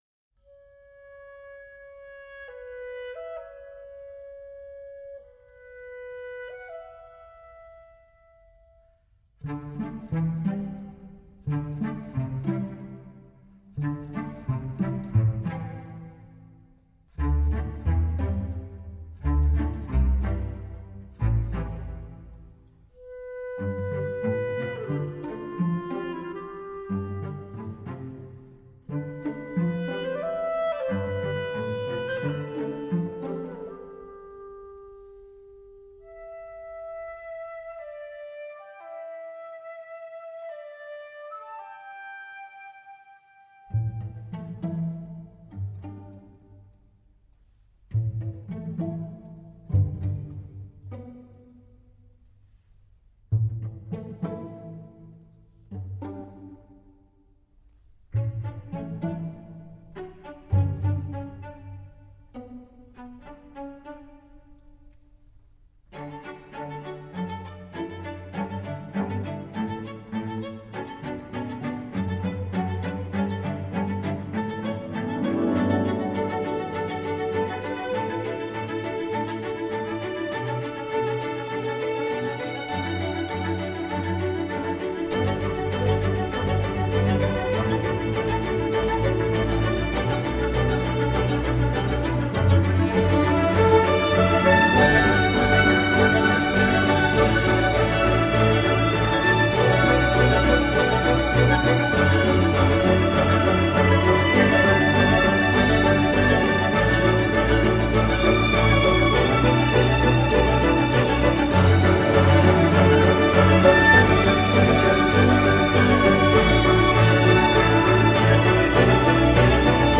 Tout est rond, charmant et souvent féerique.